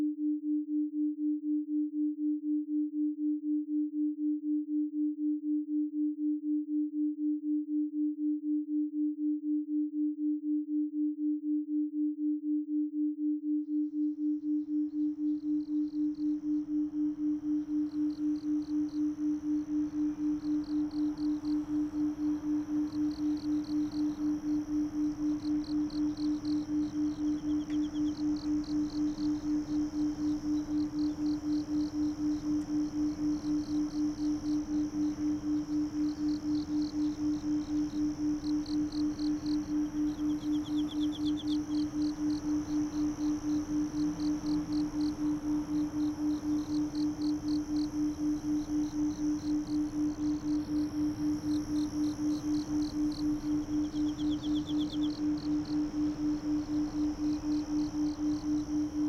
sound_1 クラシック風の曲の裏側で鳴る“うなり”に気付きましたか？